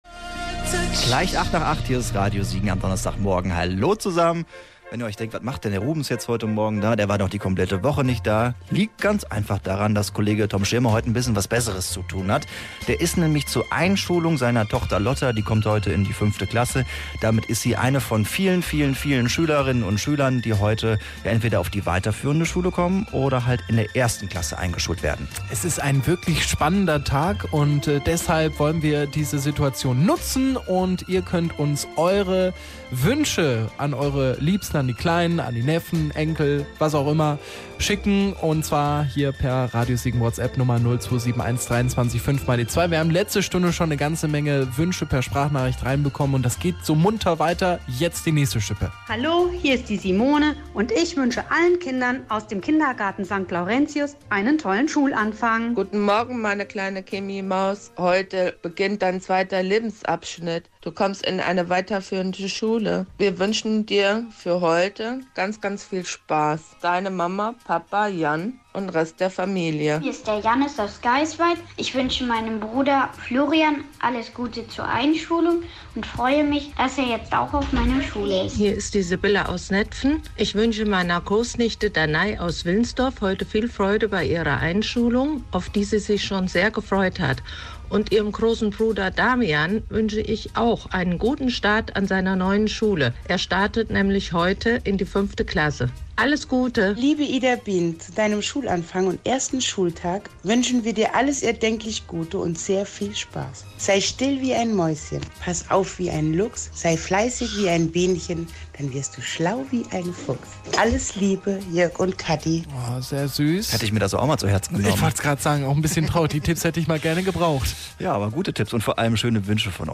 Da ja nur die engsten Verwandten mit zur Schule kommen durften, haben wir Euch Wünsche per WhatsApp Sprachnachricht schicken lassen.